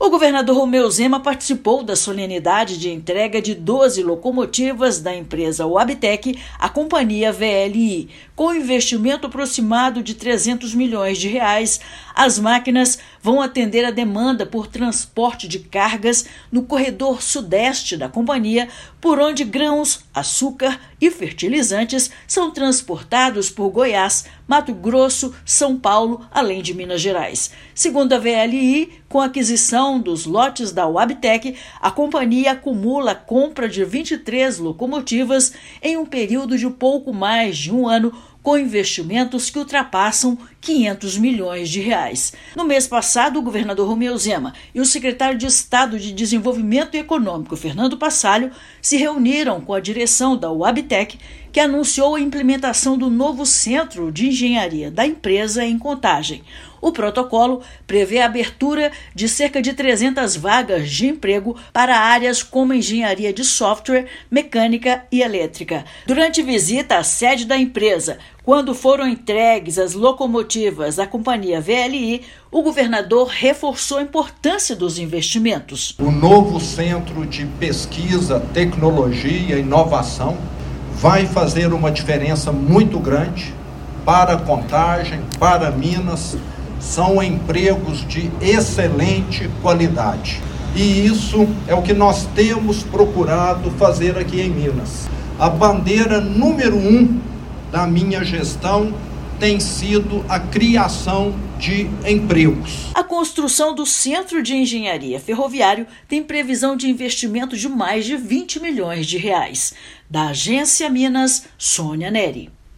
Com investimento de R$ 300 milhões, as novas composições são mais potentes, econômicas e escoará a produção agrícola estadual. Ouça a matéria de rádio: